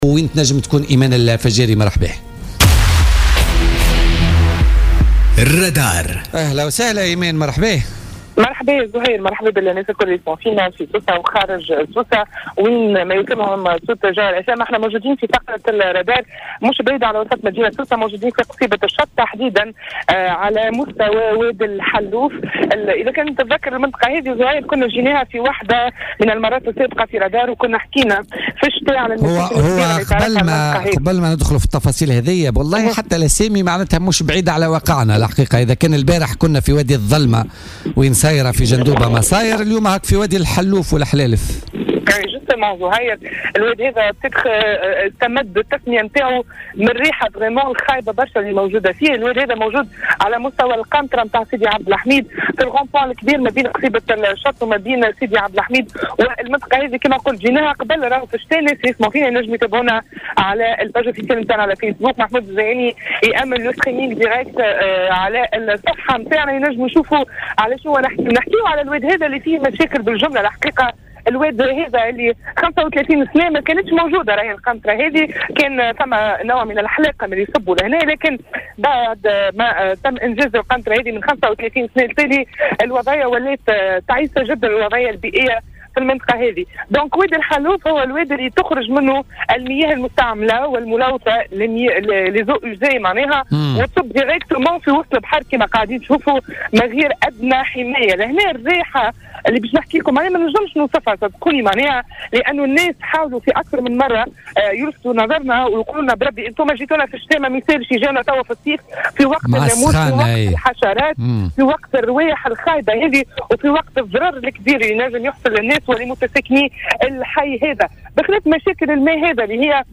تنقّل فريق الرّادار اليوم الخميس إلى منطقة "واد حلوف" الواقعة على مستوى سيدي عبد الحميد من جهة قصيبة الشط بسوسة.
وأكد مواطن لموفدة "الجوهرة اف أم" أنه رغم وعود السلطات لايجاد حلول لهذه المنطقة ومعالجة مياه الوادي إلا انه لم يتم تحقيق أي من هذه الوعود.